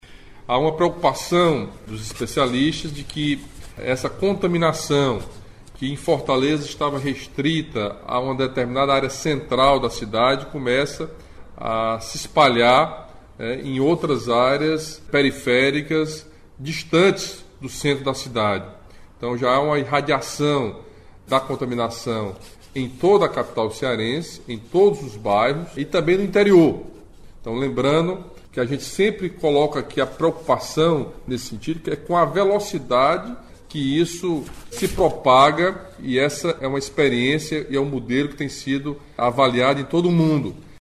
A informação foi repassada pelo governador Camilo Santana neste sábado (4), em transmissão ao vivo por suas redes sociais, momento em que o chefe do Executivo estadual explicou que a medida foi tomada levando em consideração questões técnicas e científicas.